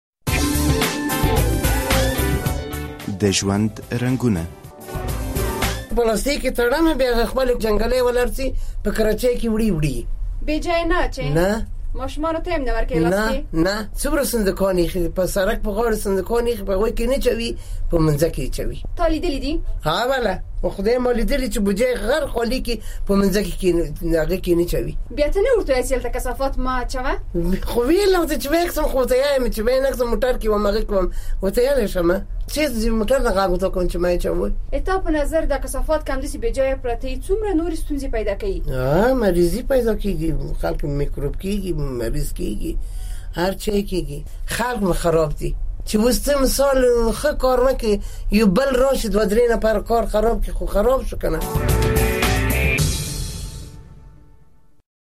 د ژوند په رنګونو کې په دې لړۍ کې دا ځل له داسې یوې میرمنې سره غږیږو چې ډیره زړه شوې خو دا چې خپله سیمه څنګه پاکه ساتي له دې به یې واورو: